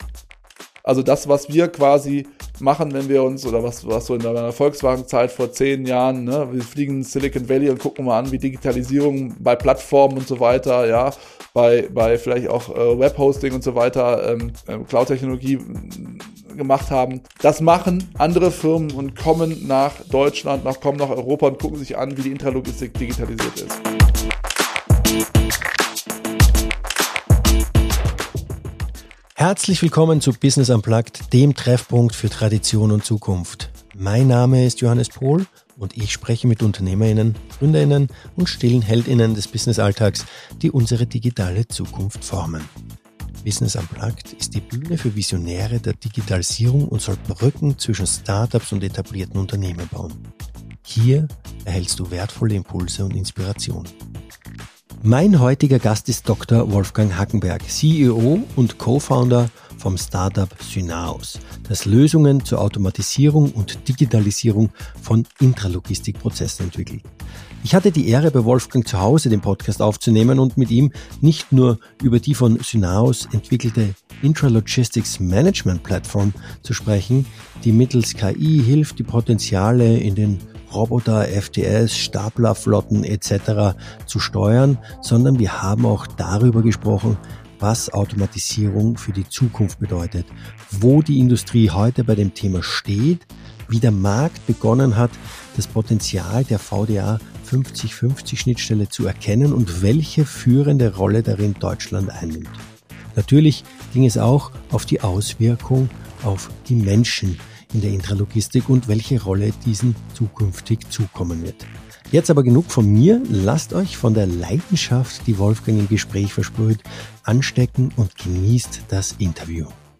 "Business unplugged" ist ein Interview-Podcast rund um das Thema Digitalisierung.